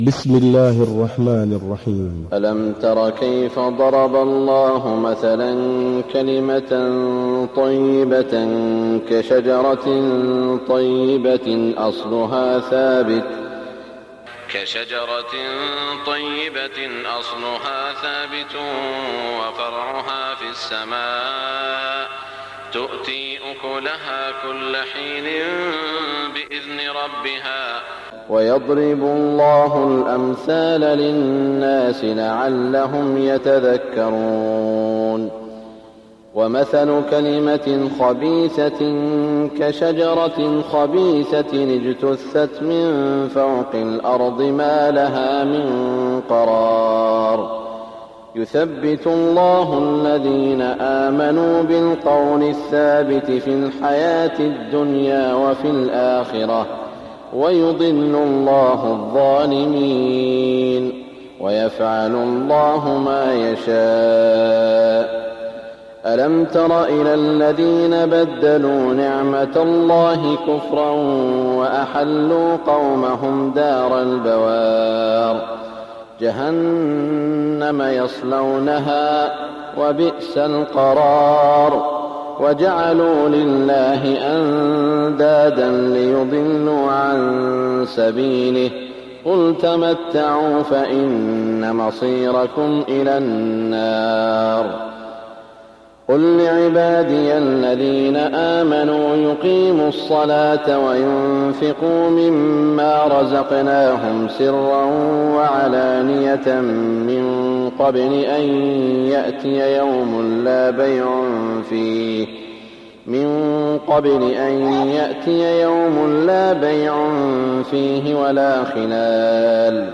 صلاة الفجر 1414هـ من سورة إبراهيم > 1414 🕋 > الفروض - تلاوات الحرمين